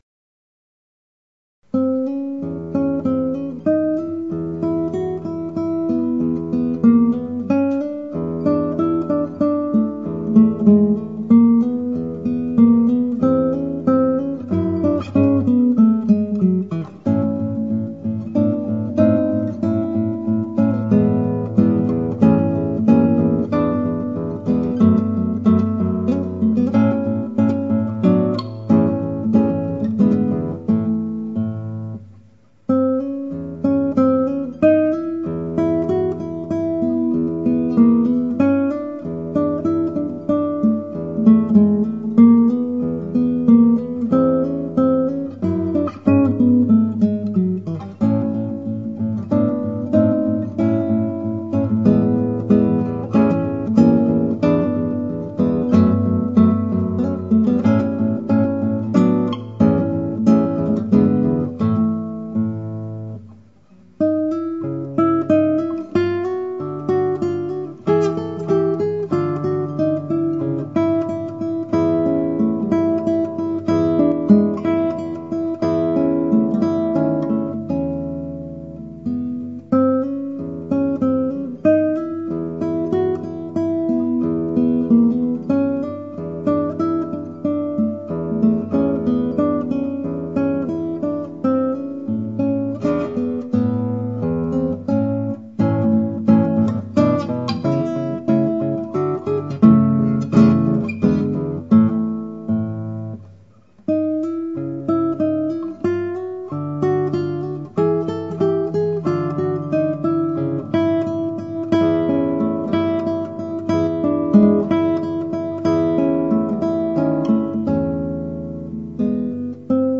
モーツァルト : ピアノソナタK331の第一変奏 (アマチュアギター演奏)
(アマチュアのクラシックギター演奏です [Guitar amatuer play] )
今回、録音した演奏では楽譜には書き込んでいませんがスラーを多く使っています。また前半部、後半部の最後の和音のC#は弾いていません。
テンポとしてはもう少し早いかもしれません。まだまだ練習不足でとくに和音は完全に押さえられてなく汚いです。